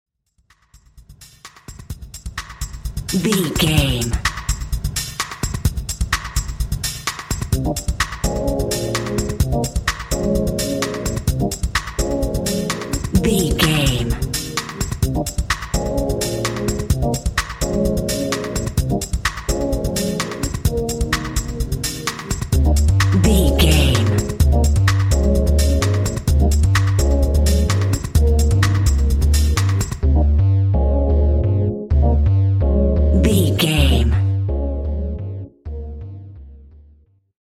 Aeolian/Minor
B♭
electronic
dance
synths
jazz drums
jazz bass
jazz guitar
jazz piano